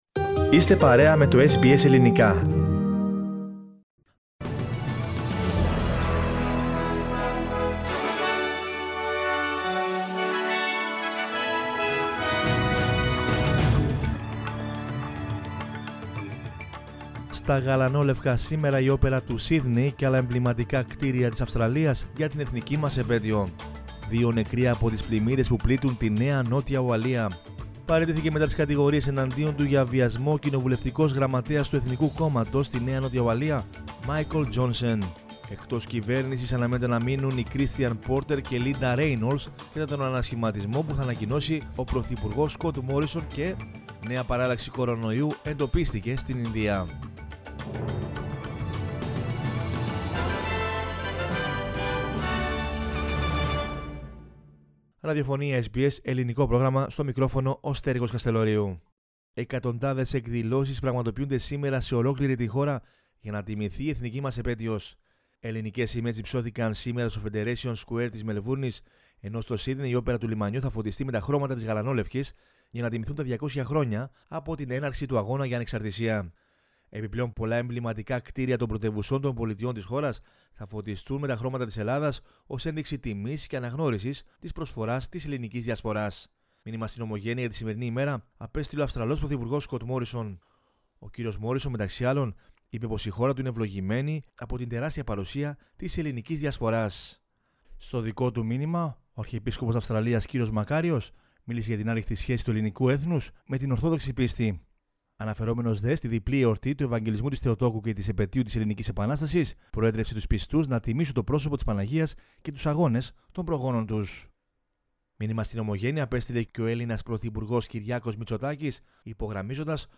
News in Greek from Australia, Greece, Cyprus and the world is the news bulletin of Thursday 25 March 2021.